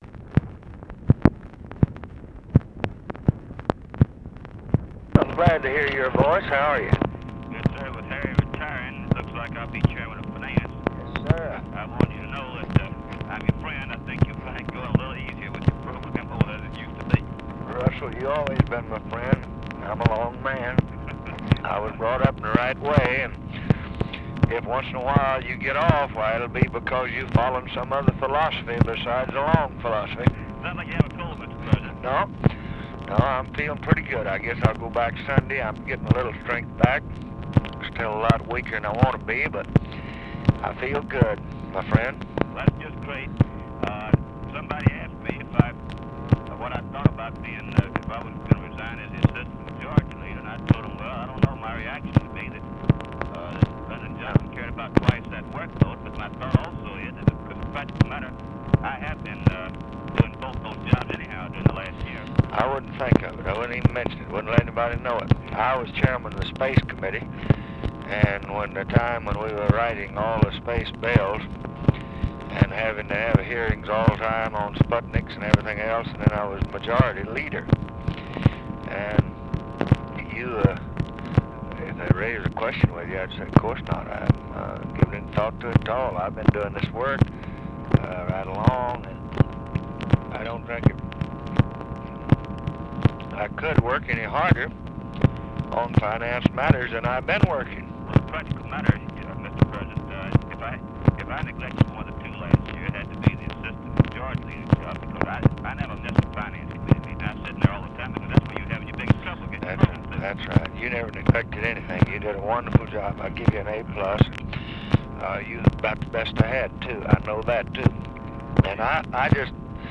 Conversation with RUSSELL LONG, November 12, 1965
Secret White House Tapes